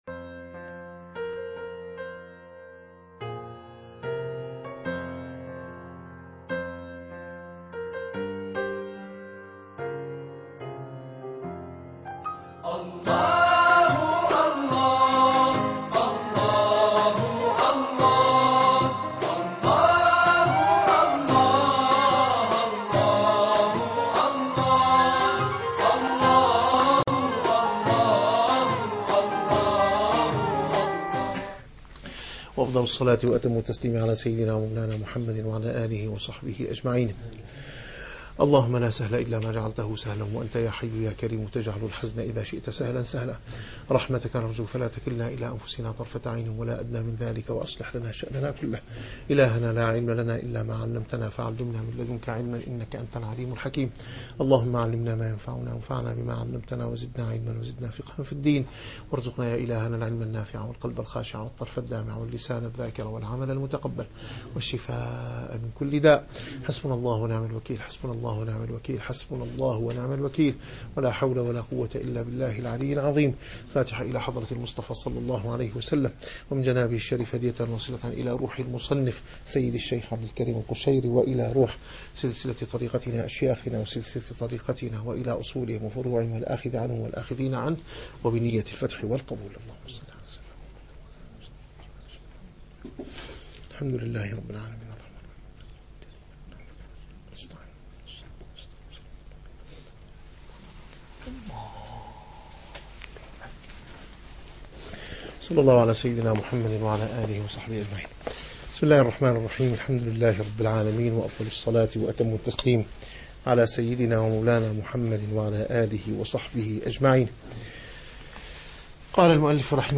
- الدروس العلمية - الرسالة القشيرية - الرسالة القشيرية / الدرس الثامن والثلاثون.